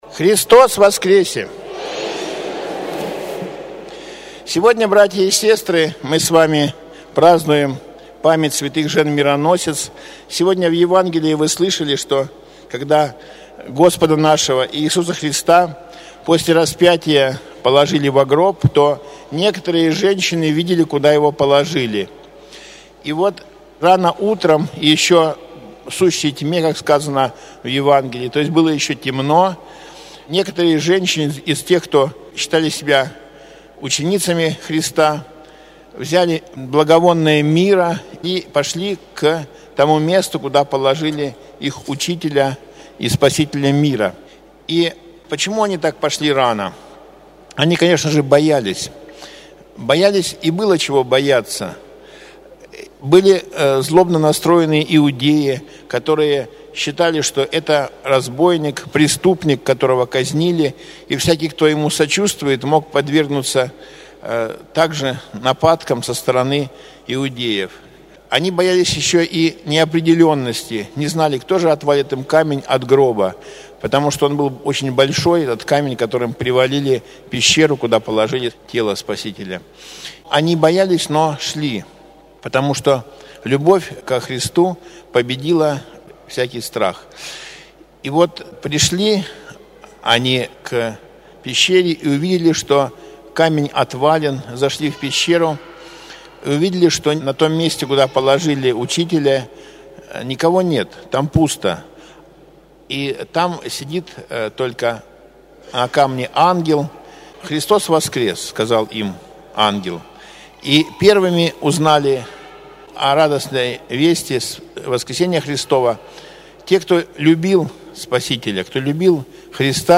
ПРОПОВЕДЬ
Проповедь на Литургии